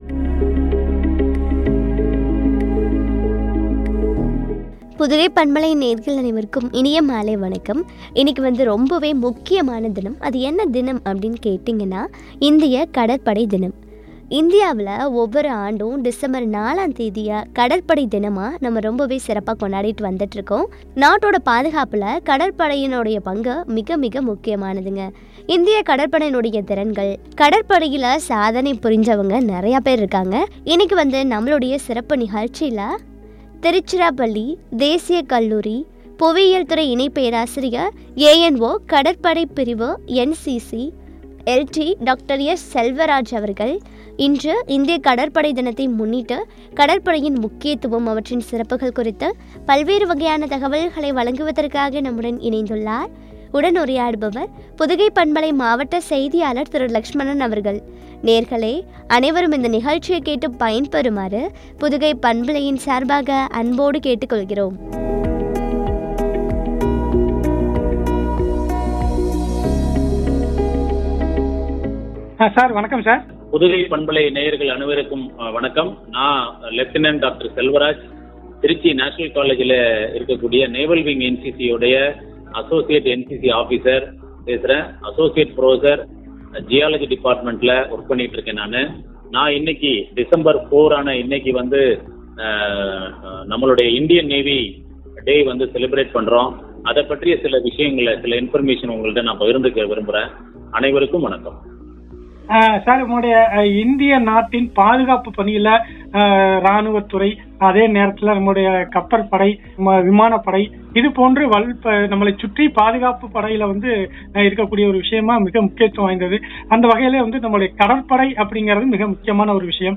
” கடற்படையின் முக்கியத்துவம் ” குறித்து வழங்கிய உரையாடல்.